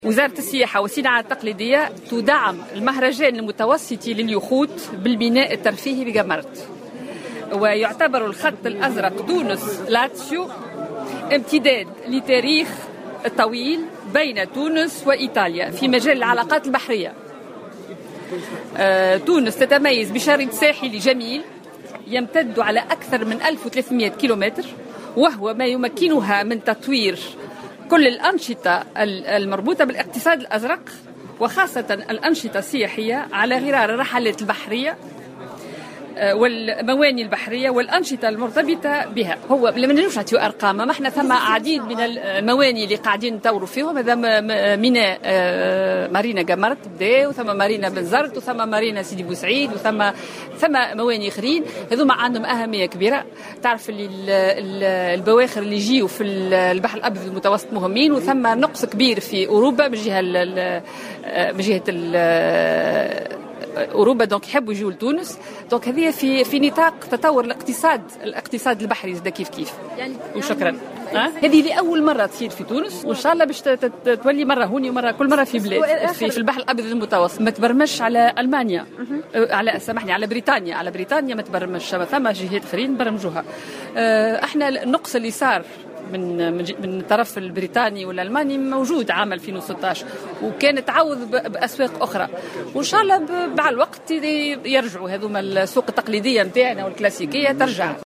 وقالت وزيرة السياحة في تصريح لمراسل الجوهرة أف أم، إن تونس تعمل على استغلال النقص الحاصل في عدد الموانئ الترفيهية في أوروبا من خلال تثمين الموانئ التونسية لتكون قادرة على استقبال اليخوت وهو ما من شأنه أن يساهم في تطوير الاقتصاد البحري التونسي، أو ما يسمى بـ"الاقتصاد الأزرق".